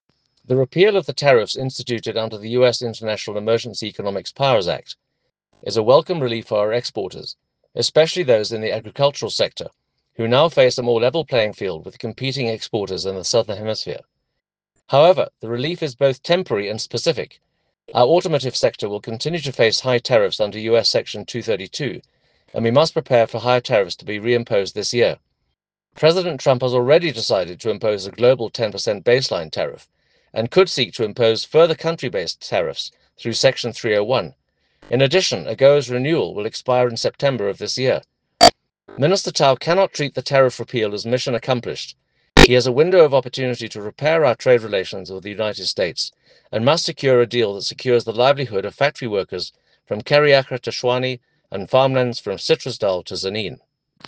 soundbite by Toby Chance MP.